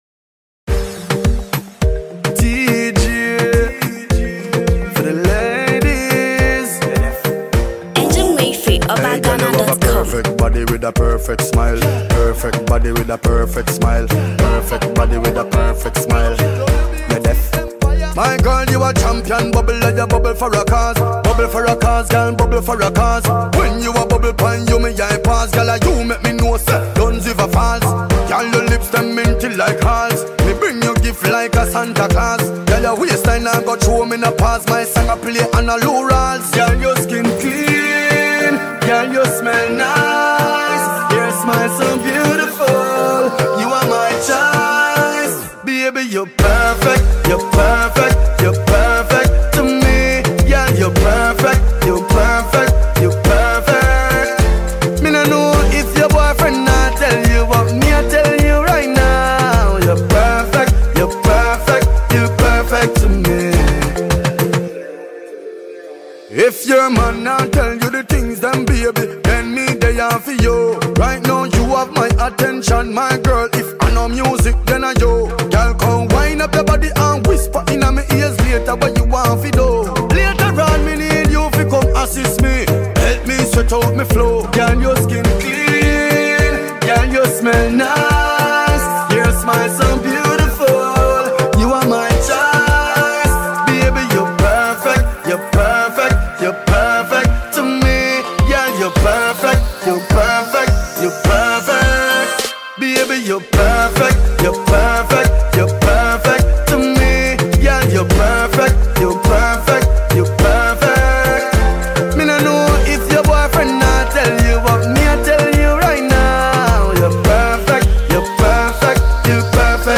Reggae/Dancehall
Mad tune fi di dancehall lovers!!